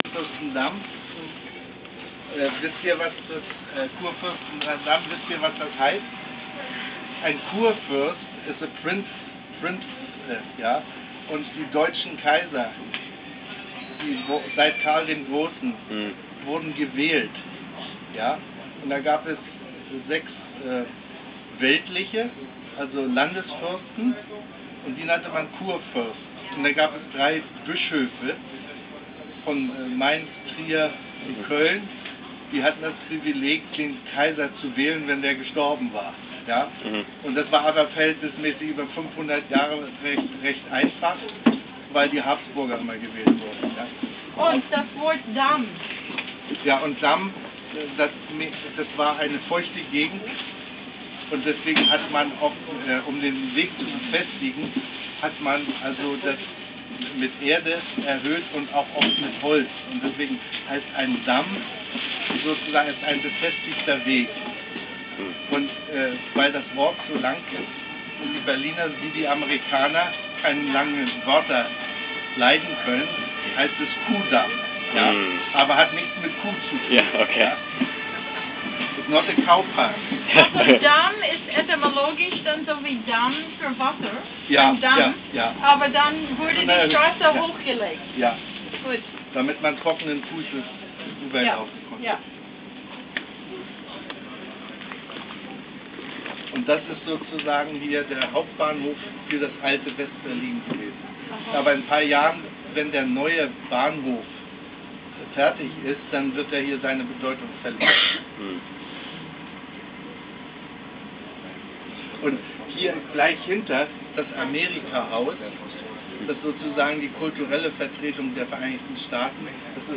explains everything on our tour in the bus.